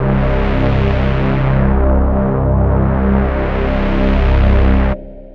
Wasp Bass.wav